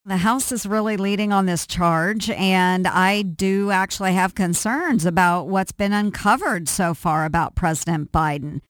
She commented briefly Wednesday during a conference call with Iowa reporters.